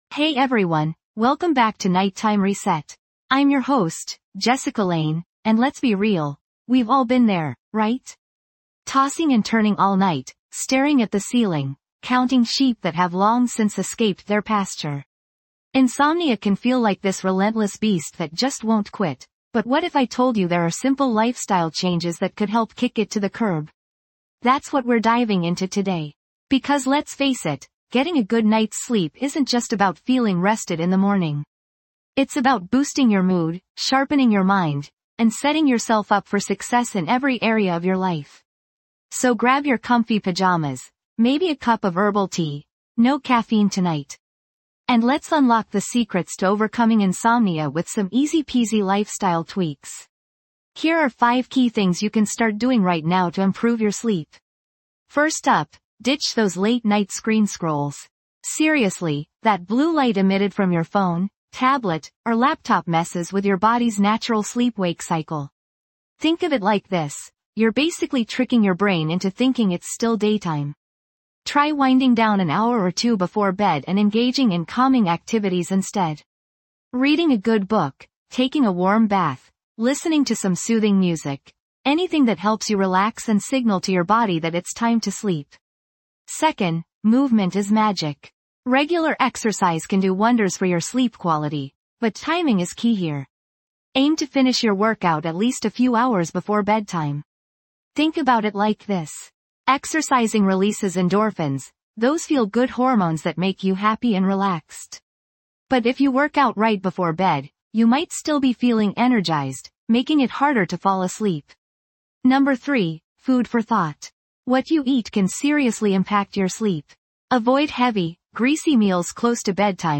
This podcast offers a nightly ritual designed to help you unwind, de-stress, and prepare for a peaceful night's rest. Through guided meditations, relaxing soundscapes, and practical sleep hygiene tips, we'll work together to improve your sleep quality, boost your mood, and enhance your mental well-being.